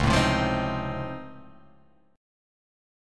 Db+M9 chord